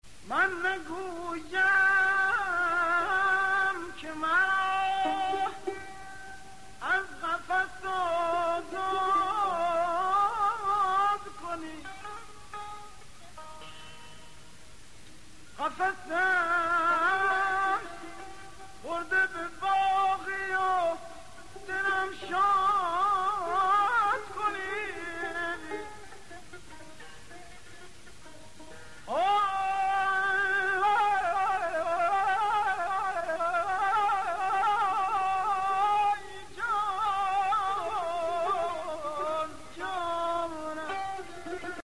تار
آواز